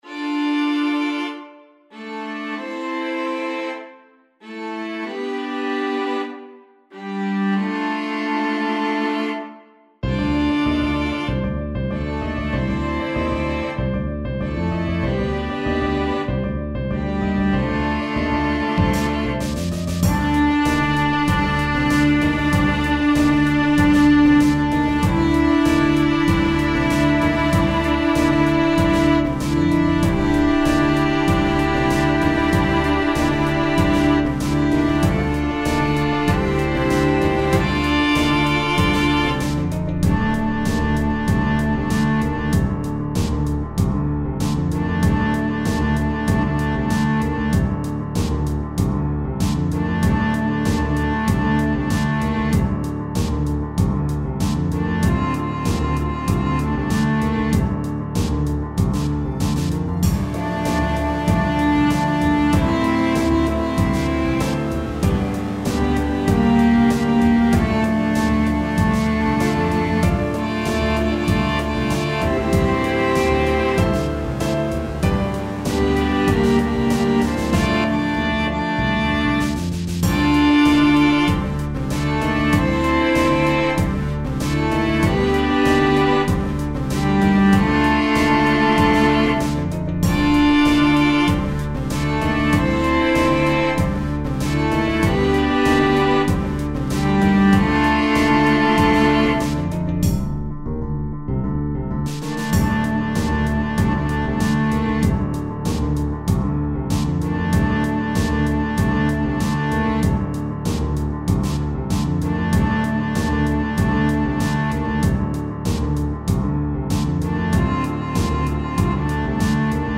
SSAA + piano/band